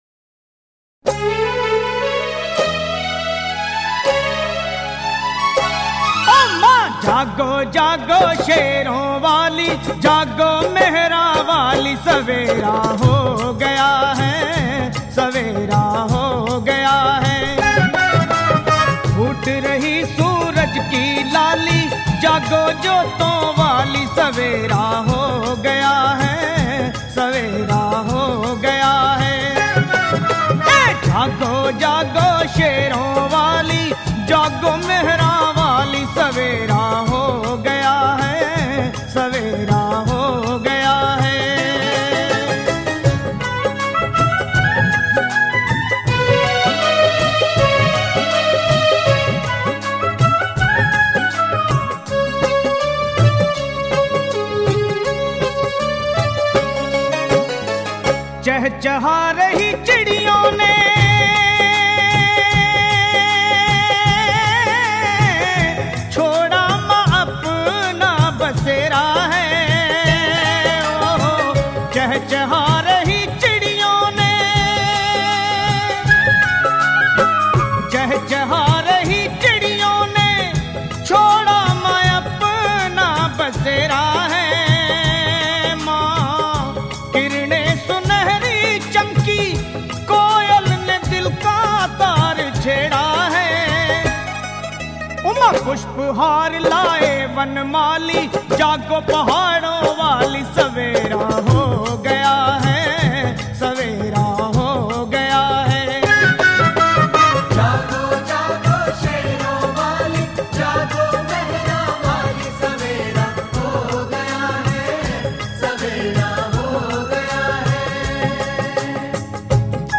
Bhajans
Navratri Special bhajan